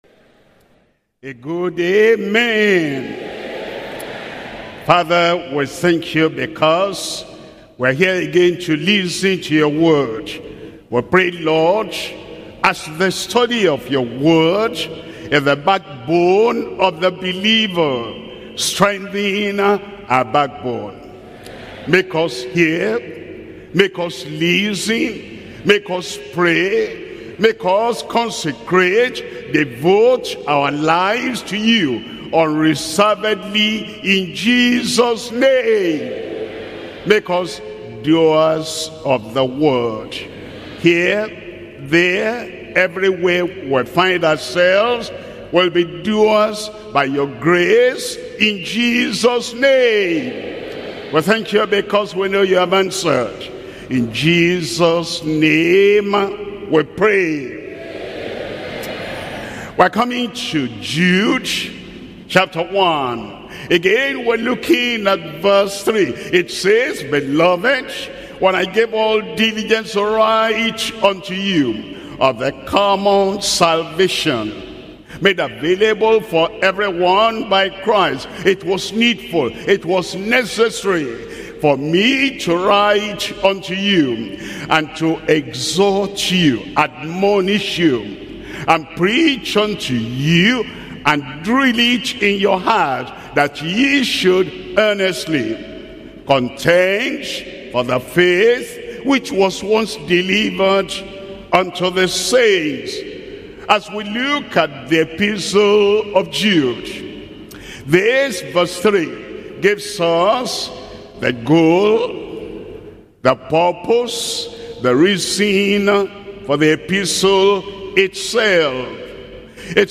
Sermons - Deeper Christian Life Ministry
Bible Study